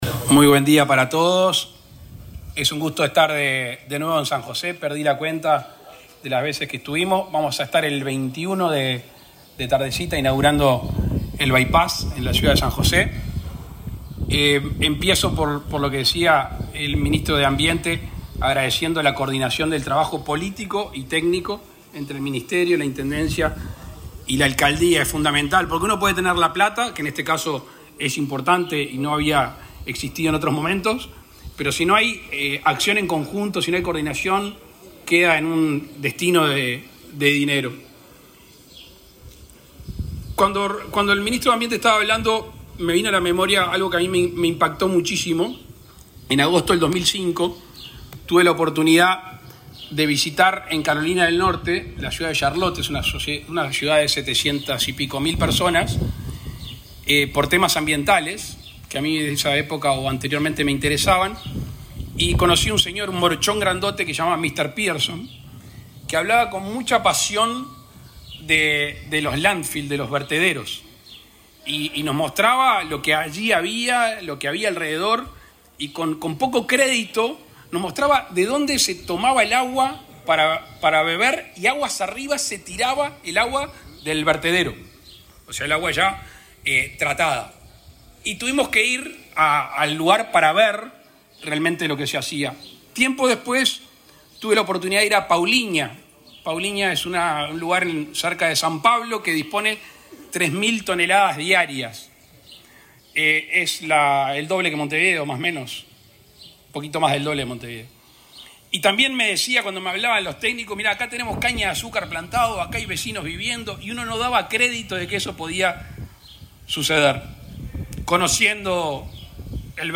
Palabras del presidente Luis Lacalle Pou
El presidente Luis Lacalle Pou participó, este miércoles 13 en San José, de la inauguración de la planta de reciclaje de Ciudad del Plata.